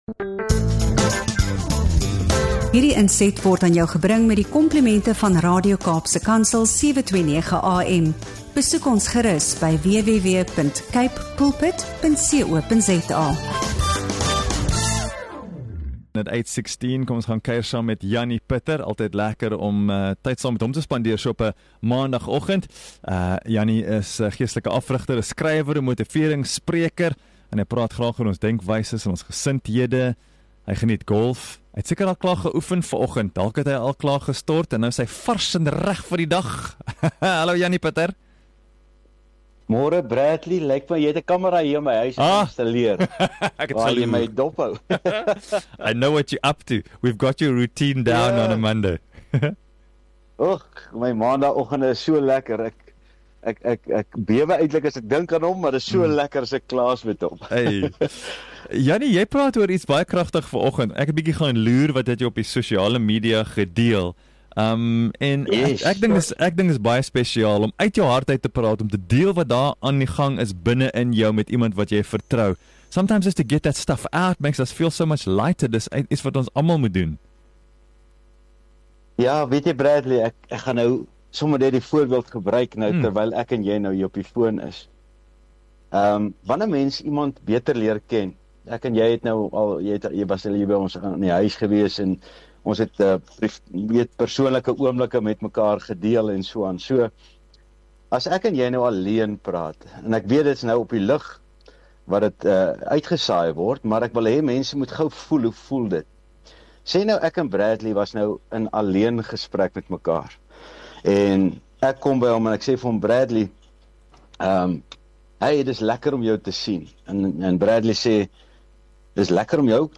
In hierdie diep en eerlike gesprek gesels ons